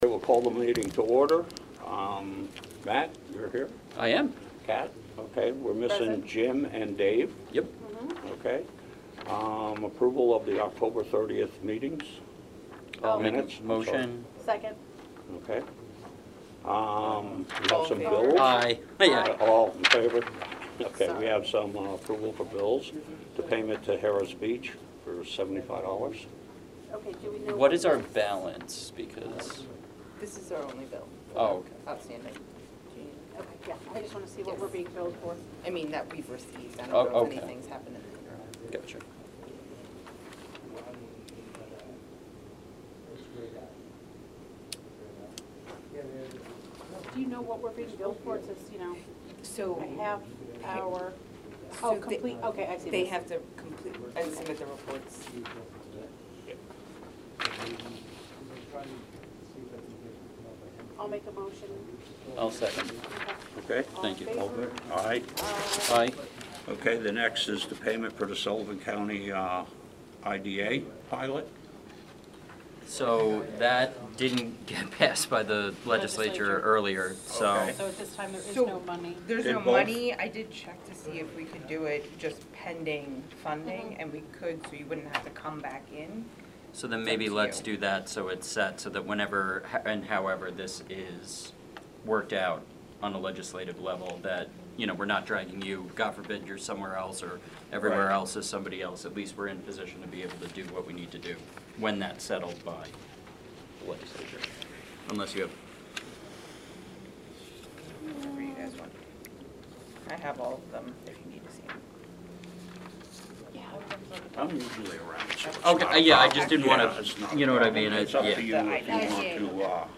Meetings are open to the public.
All occur at 1 p.m. in either the Legislative Hearing Room or Committee Room of the Government Center, 100 North Street, Monticello, NY.